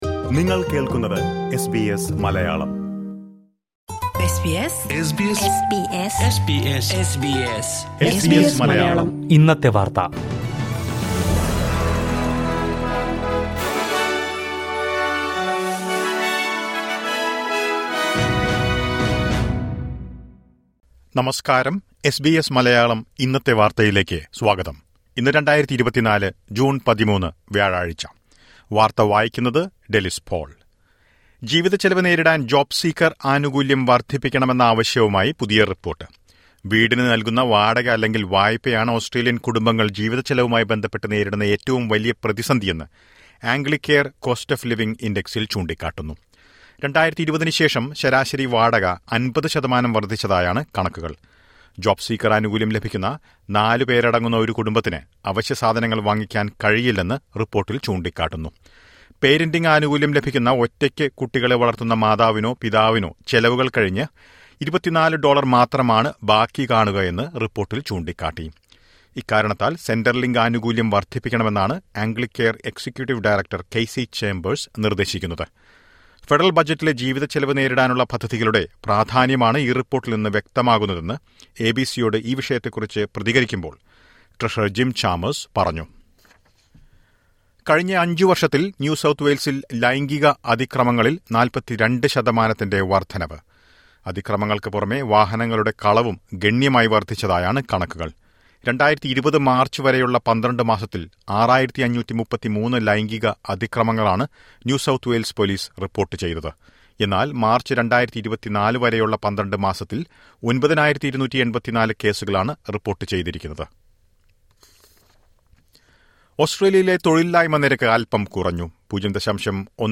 malayalam news